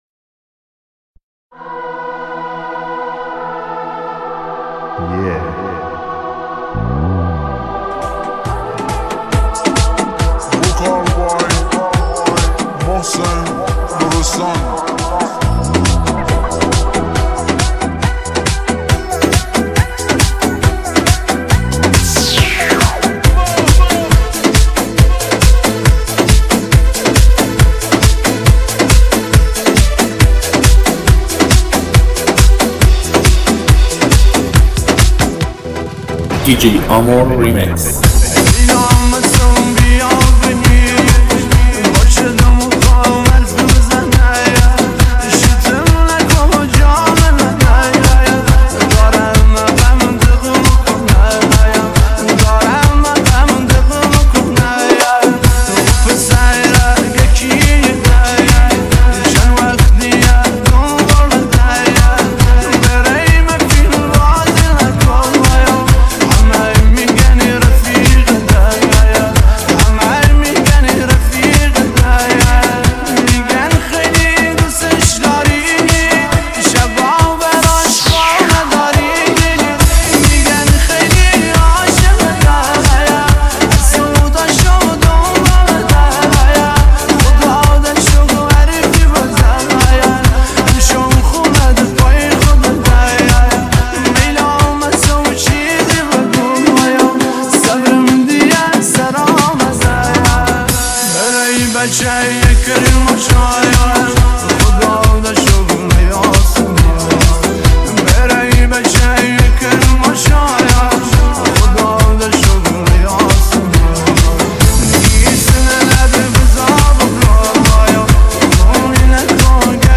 • آهنگ جدید ~ ریمیکس
شاهکار احساسی